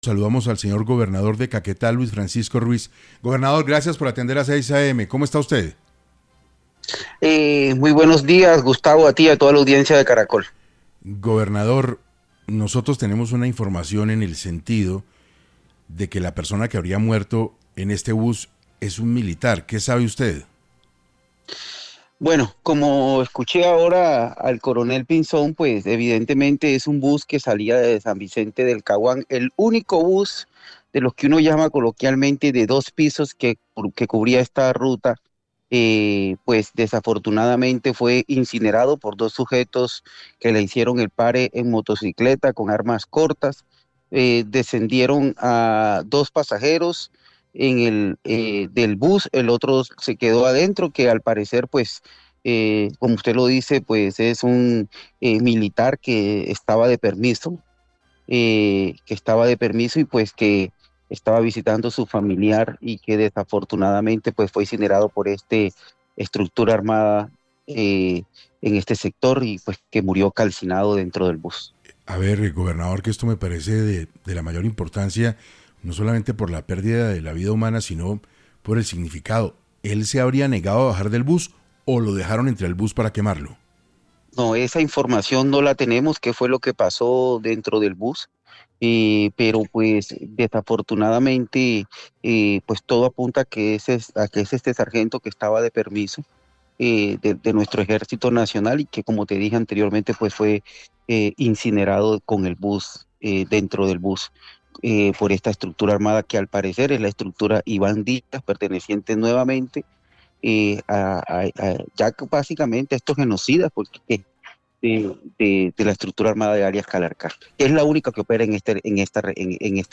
En entrevista con 6AM de Caracol Radio, el gobernador de la región Luis Francisco Ruiz señaló que, todo a punta a que un sargento en permiso murió calcinado al interior del vehículo: